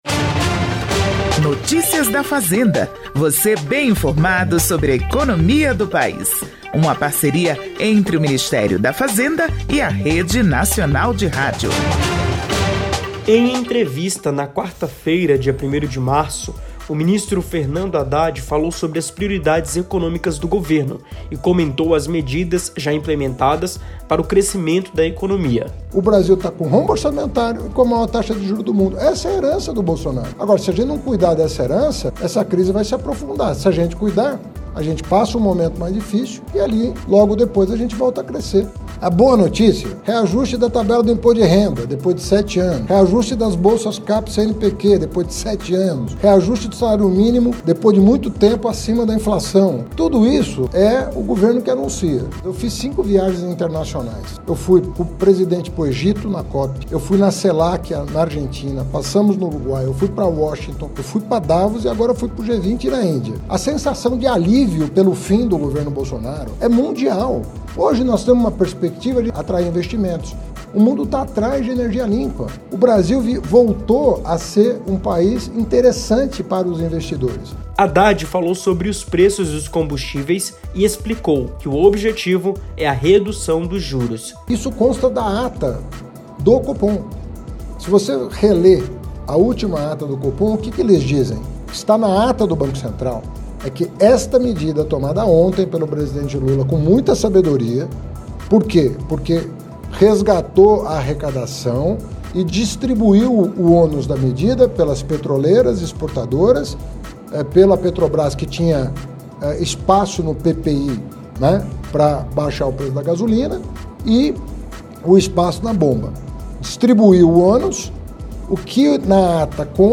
Em entrevista nesta quarta-feira, dia 1º de março, o ministro Fernando Haddad falou sobre as prioridades econômicas do governo e comentou as medidas já implementadas para o crescimento da economia.